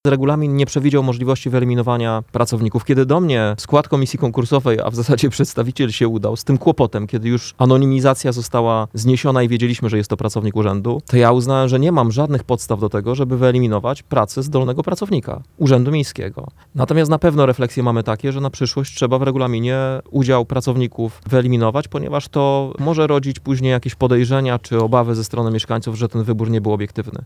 – Problematyczna kwestia zwycięstwa osoby zatrudnionej w Urzędzie Miejskim jest wynikiem źle skonstruowanego regulaminu konkursu – twierdzi prezydent.